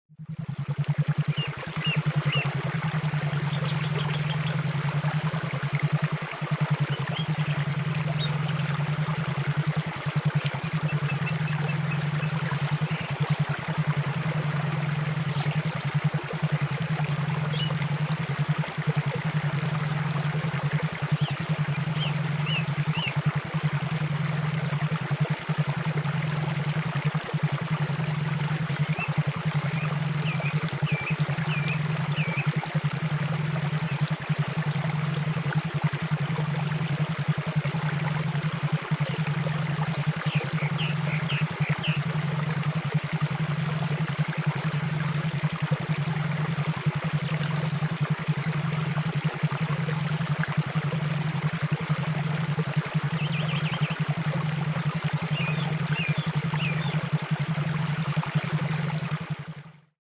• הרצועה השלישית: משלבת תדרים איזוכרונים ובינוראלים בגלי ביתא לטיפול ב- ADD ו-ADHD עם קולות מים בטבע.
דוגמה להאזנה תדרים איזוכרונים ובינוראלים לטיפול ב- ADD ו-ADHD:
אימון גלי מוח איזוכרוני לקשב וריכוז